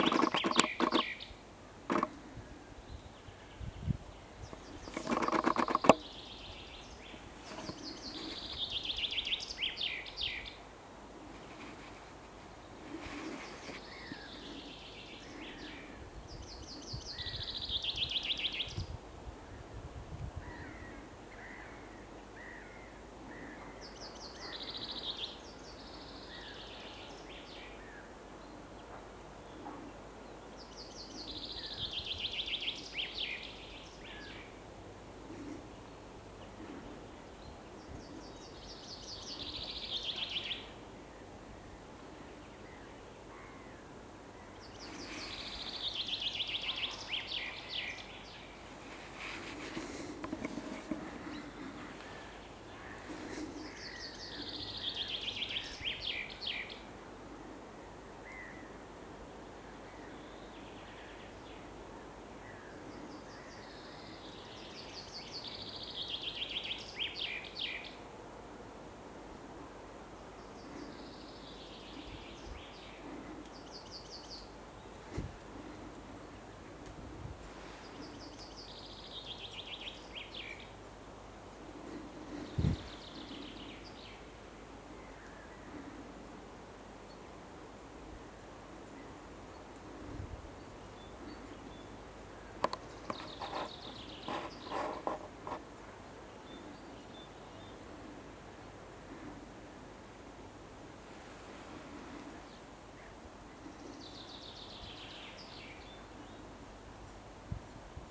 Žubīte, Fringilla coelebs
Skaits10 - 50
StatussDzied ligzdošanai piemērotā biotopā (D)
PiezīmesInteresanti, ka dziesmas beigās viena no žubītēm atkārtoja nobeigumu 2–3 reizes (ierakstā ir tikai ar 2 atkārtojumiem).